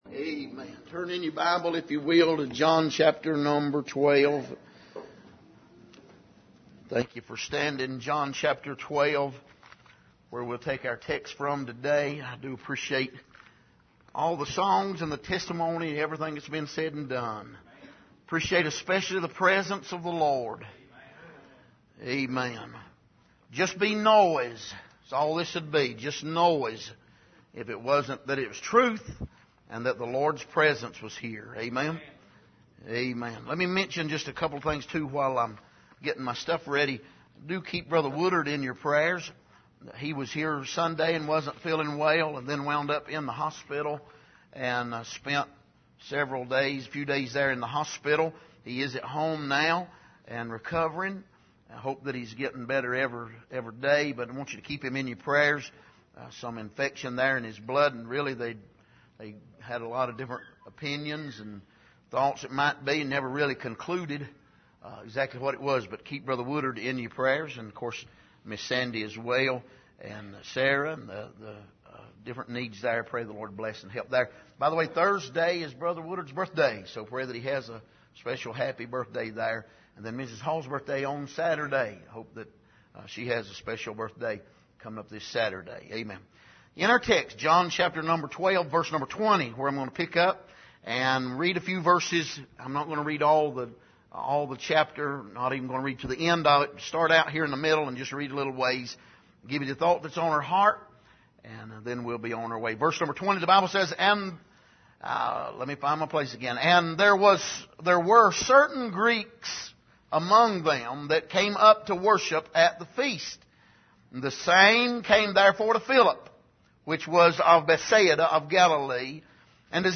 Passage: John 12:20-33 Service: Sunday Morning